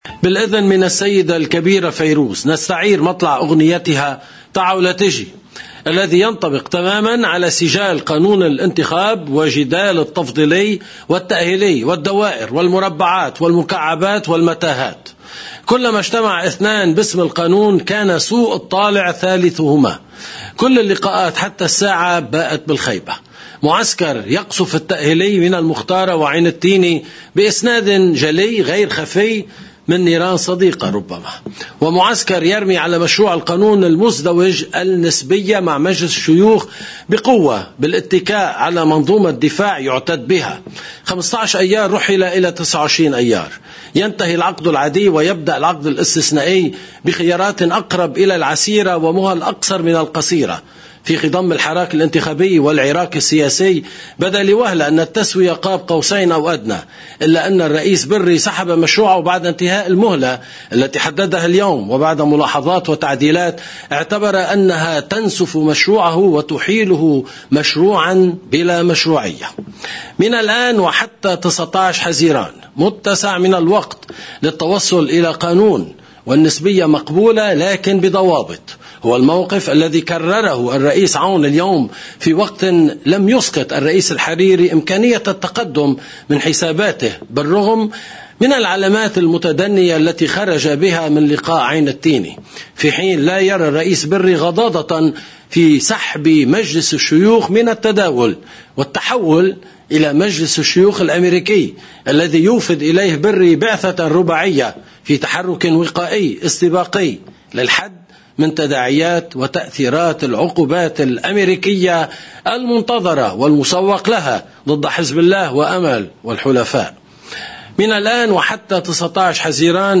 المصدر: مقدمة نشرة الـ”OTV”